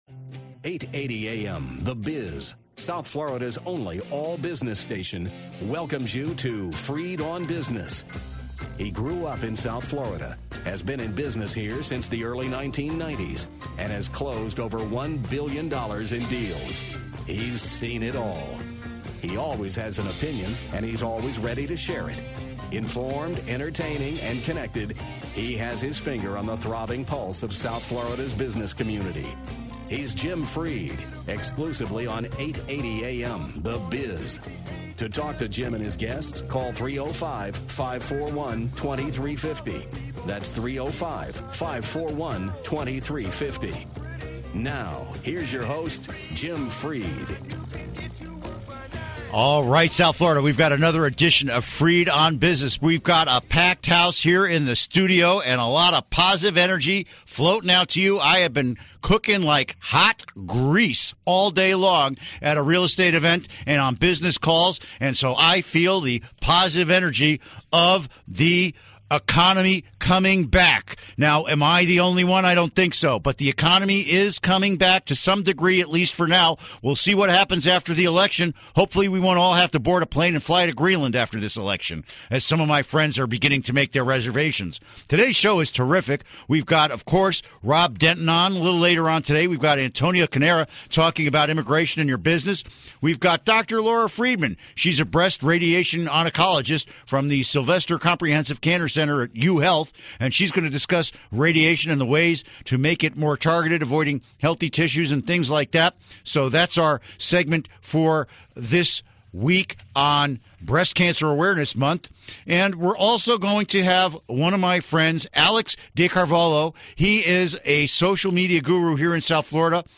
Business talk discussing Immigration reform, breast cancer treatment trends, improve the credit of your business and social media in South Florida.